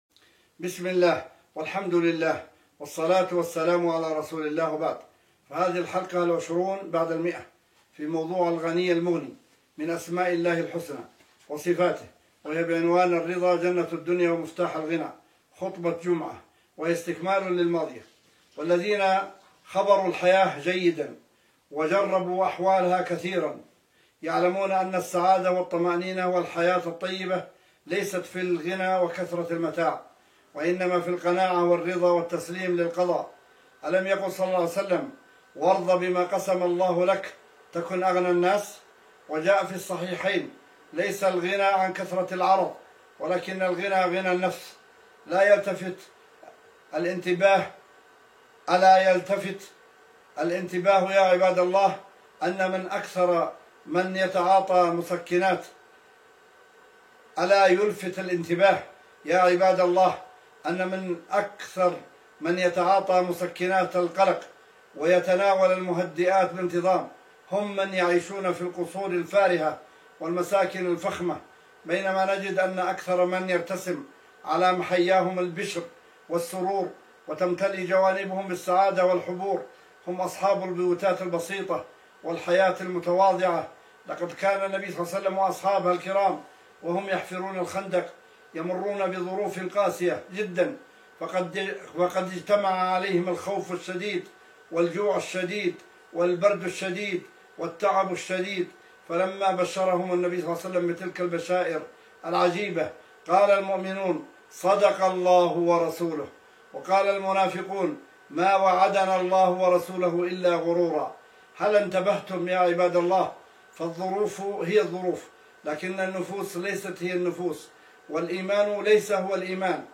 وصفاته وهي بعنوان: *الرضا جنة الدنيا ومفتاح الغنى (خطبة) :